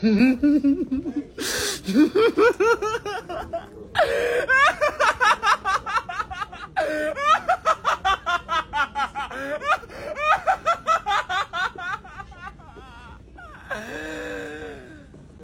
More Obnoxious Laughing To Play During Class